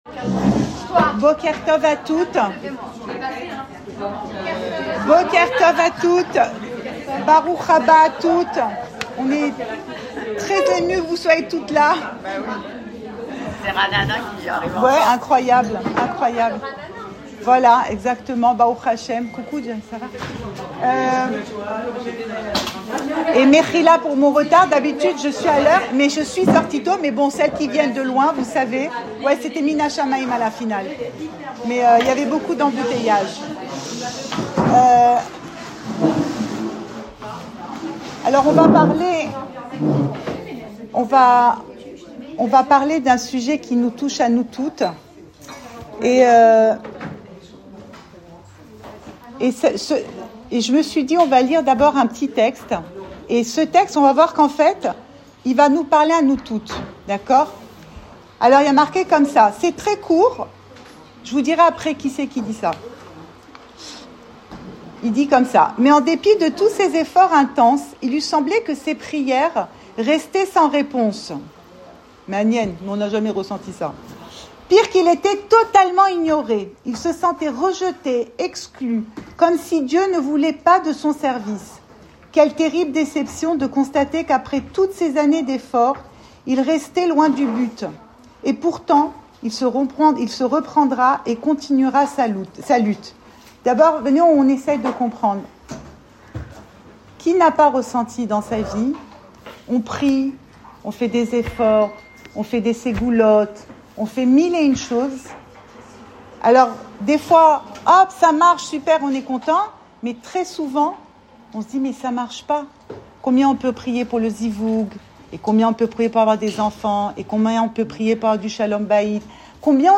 Cours audio Emouna Le coin des femmes Le fil de l'info Pensée Breslev - 21 janvier 2026 21 janvier 2026 Une prière sans réponse ? Enregistré à Tel Aviv